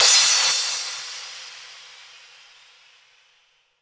cymbal01.ogg